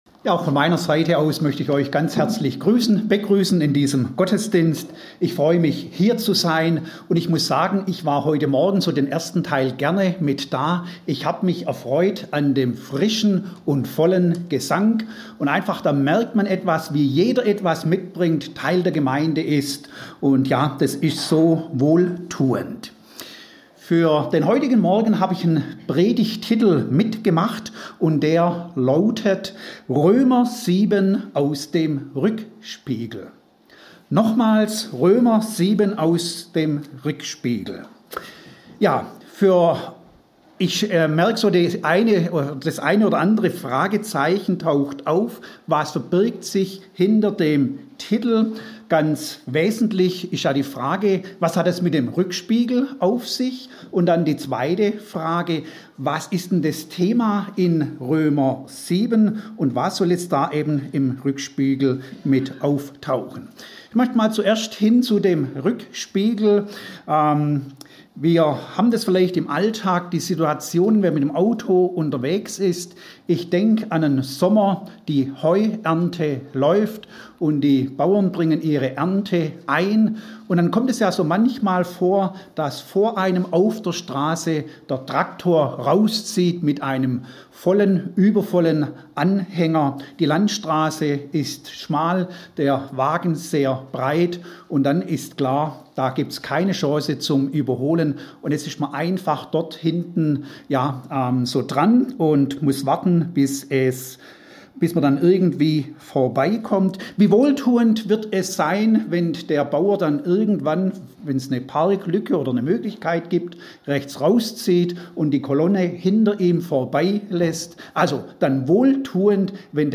Deshalb wünschen wir dir, dass du mit diesen Predigten Gott erlebst.